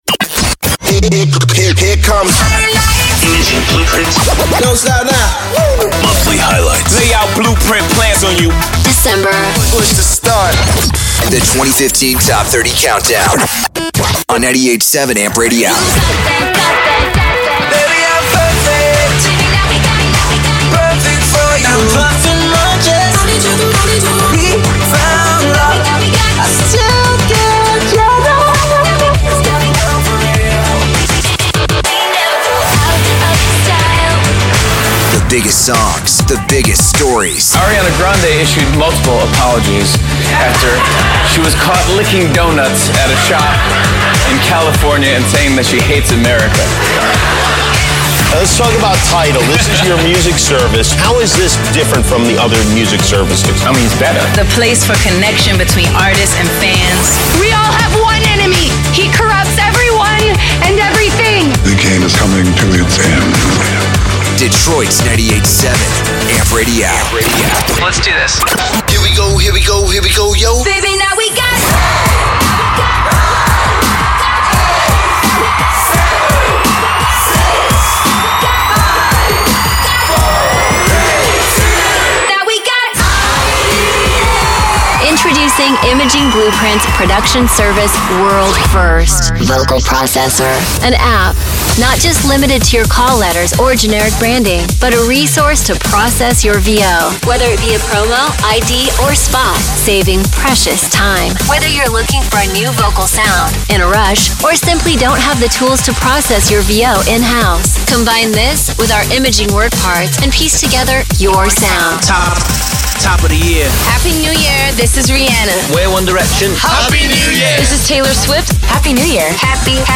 Our clients Amp Radio and KIIS are used to demonstrate 'IB' production alongside the world famous Z100 in this month's highlights audio.